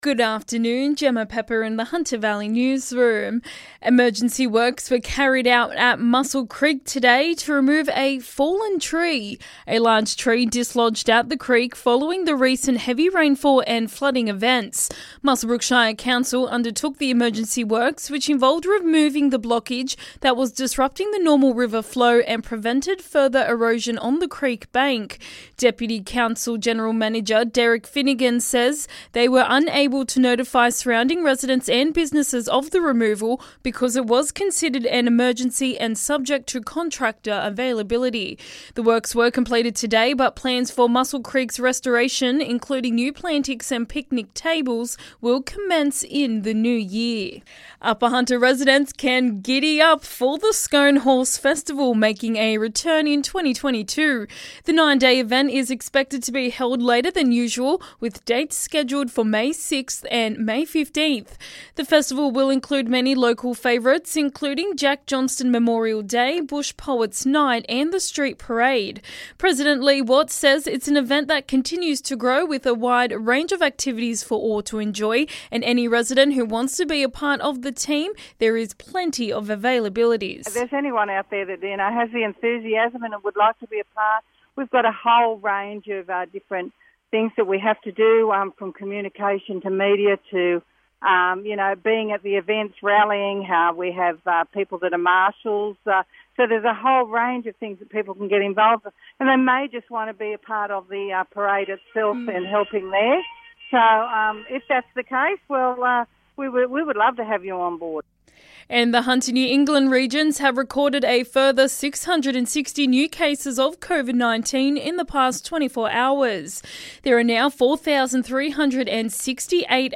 LISTEN: Hunter Valley Local News Headlines